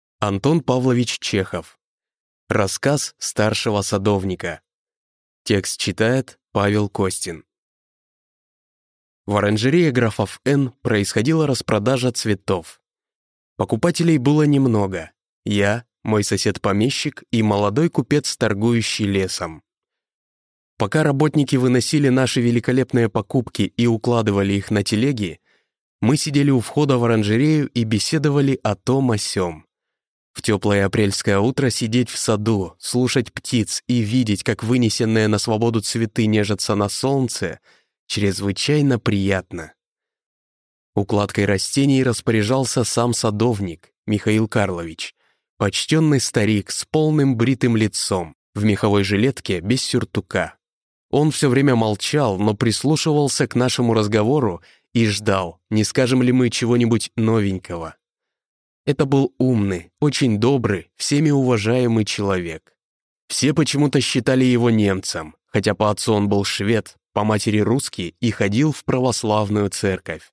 Аудиокнига Рассказ старшего садовника | Библиотека аудиокниг